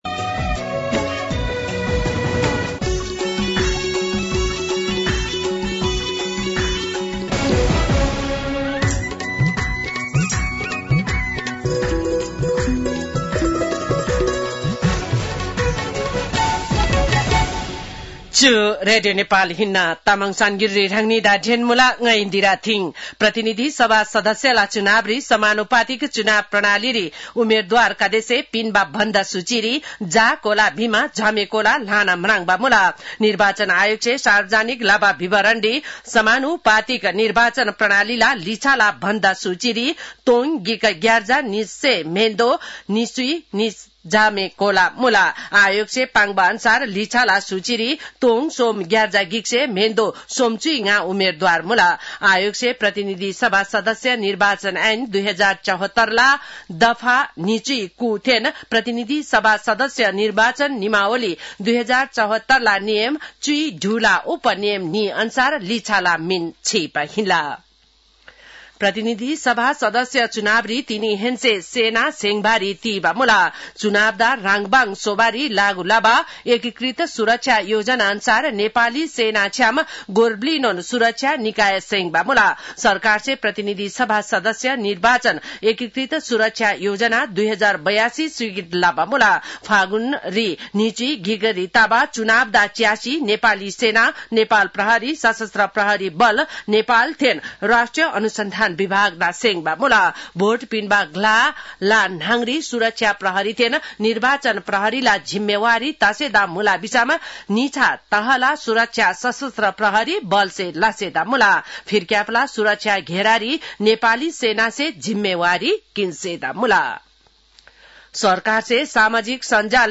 तामाङ भाषाको समाचार : २१ माघ , २०८२